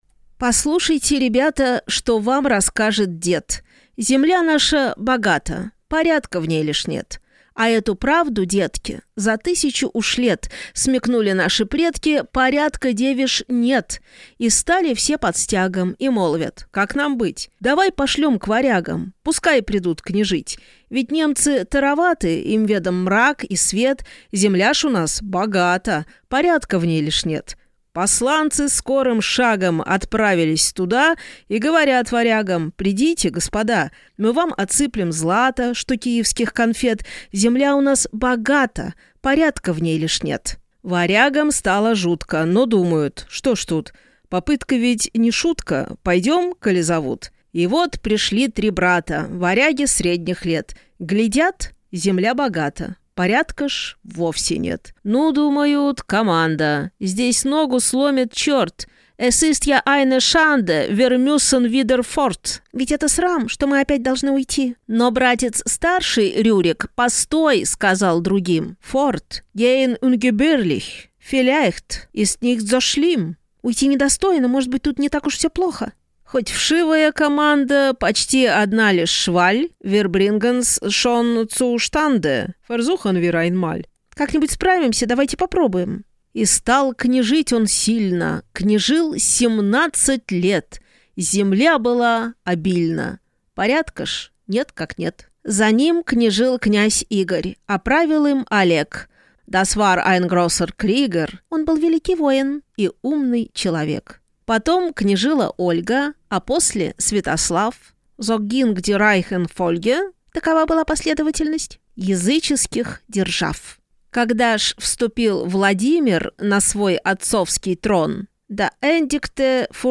Аудиокнига Земля наша богата, порядка в ней лишь нет… (сборник) | Библиотека аудиокниг